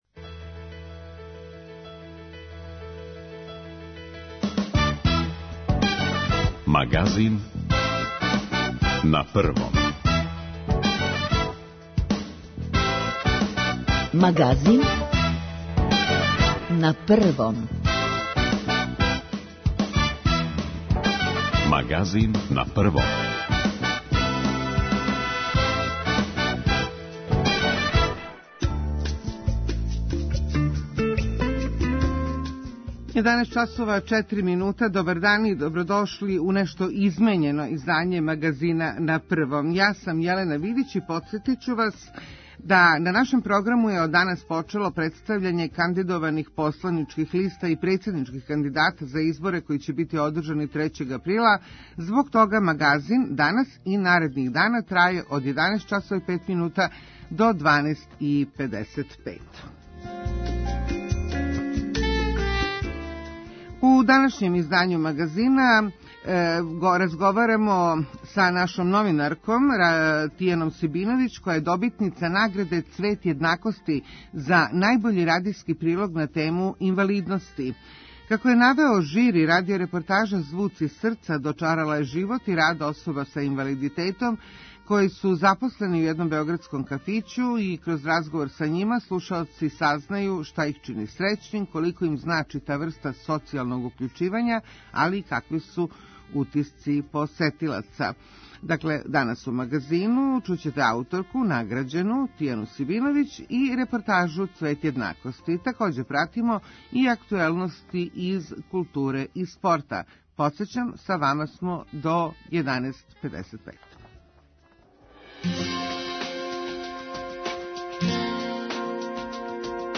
Како је навео жири, радио-репортажа „Звуци срца“ дочарала је живот и рад особа са инвалидитетом који су запослени у једном београдском кафићу - кроз разговор са њима слушаоци сазнају шта их чини срећним, колико им значи та врста социјалног укључивања али и какви су утисци посетилаца.